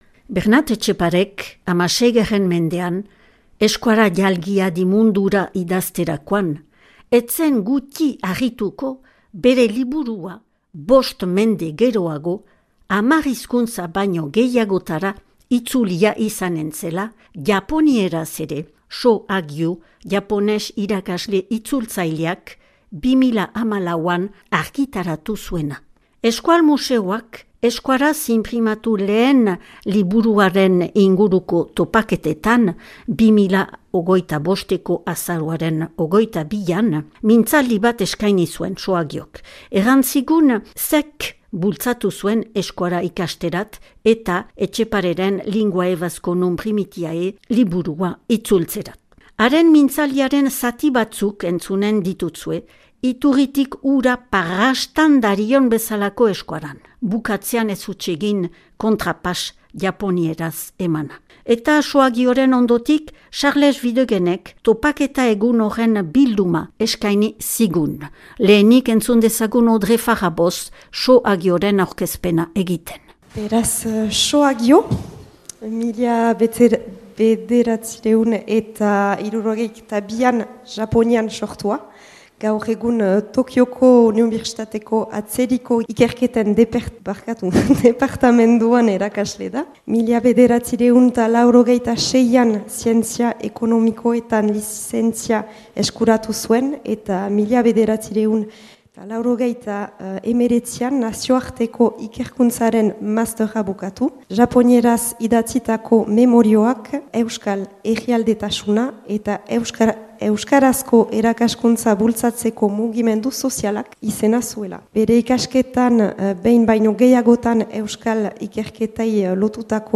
Euskaraz inprimatu lehen liburuaren inguruko topaketak, Euskal museoak antolaturik 2025eko azaroaren 22an Baionako Herriko Etxean.